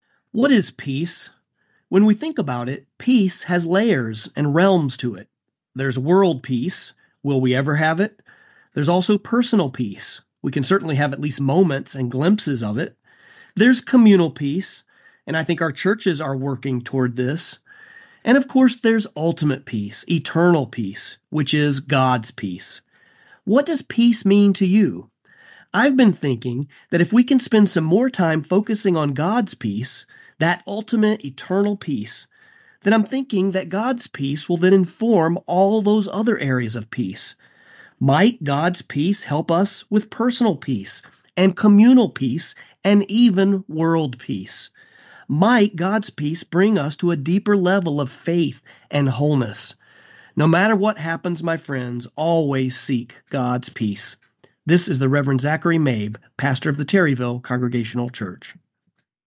"Meditation" Spot Podcasts